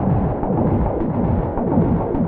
Index of /musicradar/rhythmic-inspiration-samples/105bpm
RI_RhythNoise_105-03.wav